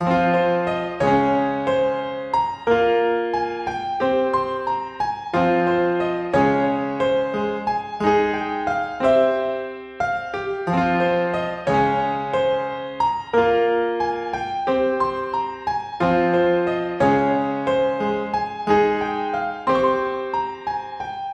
EPIC SAD KEYS 2用于Trap Hip Hop和其他类型的音乐。
Tag: 90 bpm Hip Hop Loops Piano Loops 3.59 MB wav Key : Fm FL Studio